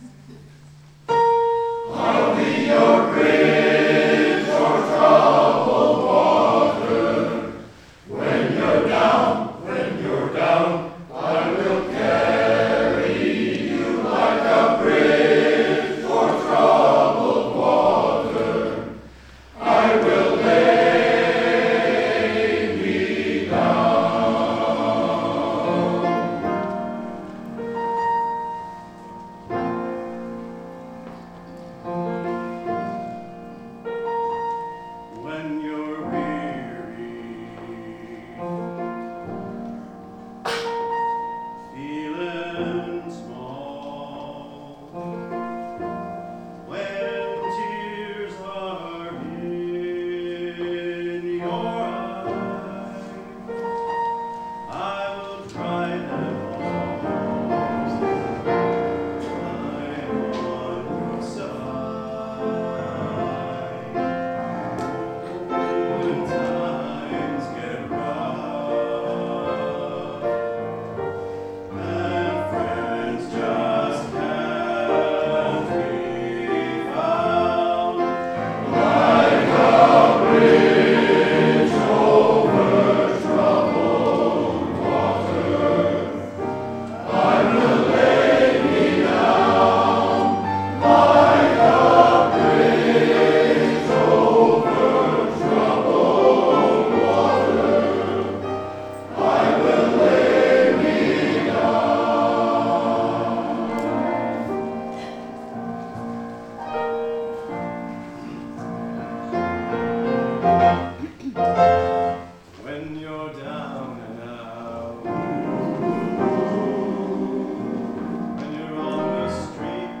Guelph Male Choir - Spring Concert - 2016